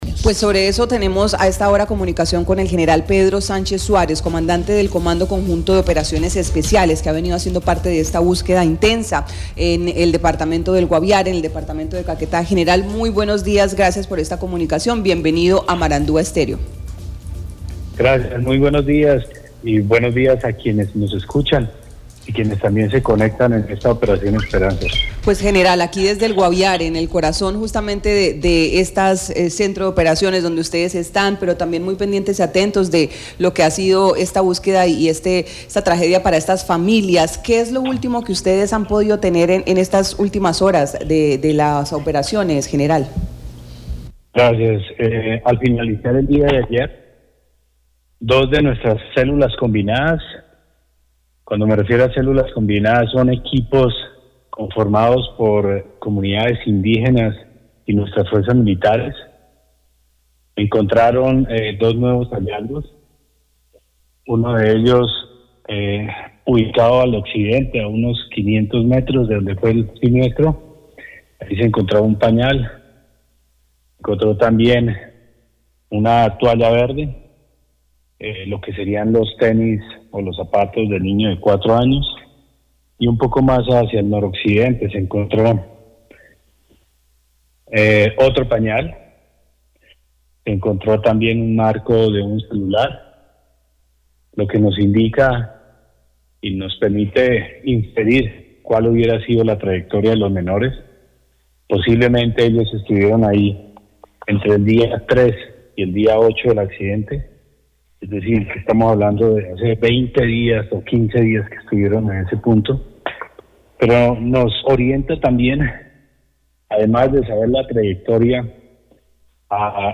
El brigadier general Pedro Sánchez Suárez, comandante del Comando Mayor de las Fuerzas Especiales, expresó en Marandua Noticias, que al finalizar el día de ayer las Fuerzas Militares y las comunidades indígenas hallaron más huellas de los menores extraviados en la selvas del Caquetá y Guaviare, entre ellos, dos pañales, un zapato al parecer del menor de 11 meses.